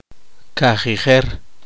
[ka.χi.’χer] adverbio four days ago